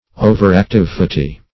overactivity - definition of overactivity - synonyms, pronunciation, spelling from Free Dictionary